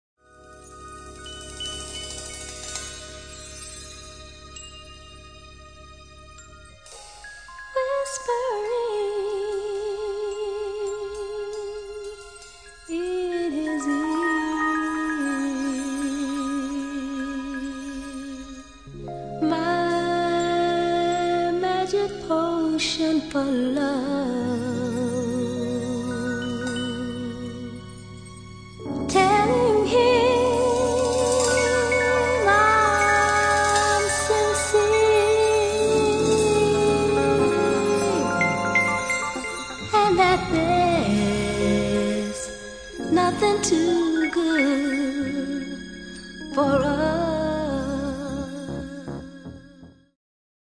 Genere:   Disco Funky